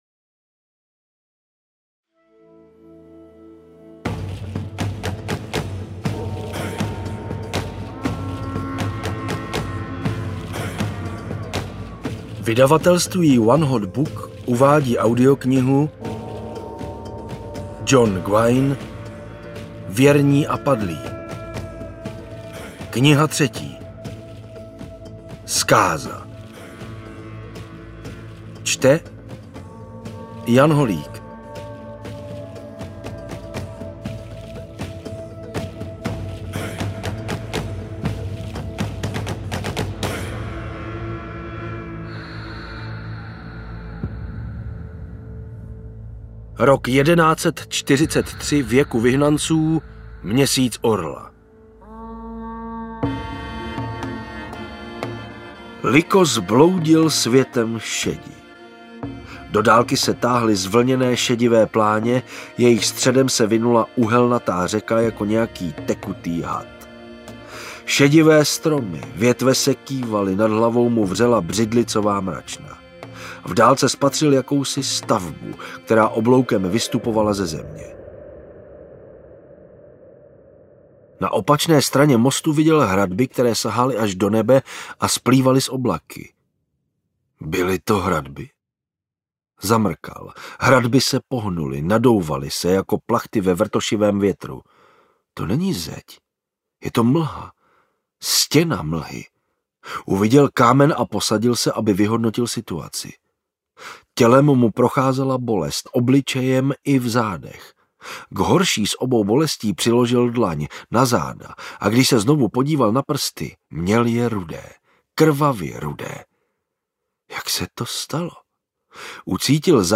Zkáza audiokniha
Ukázka z knihy